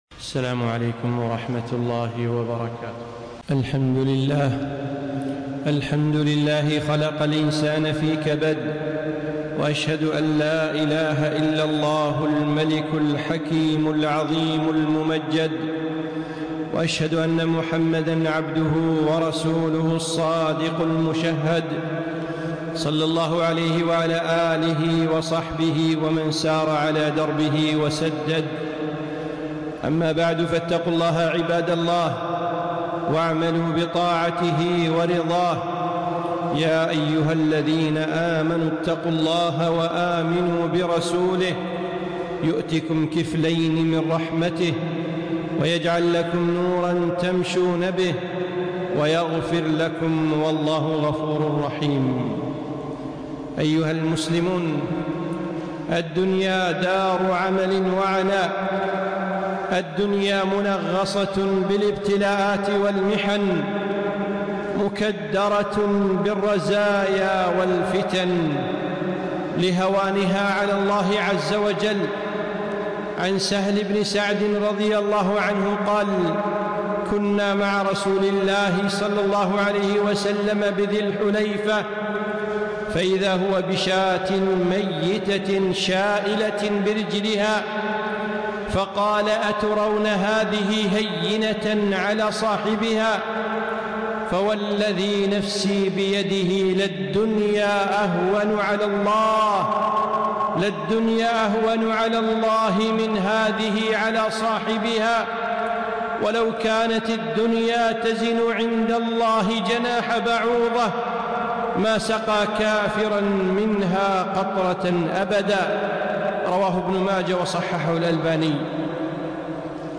خطبة - خلقت في كبد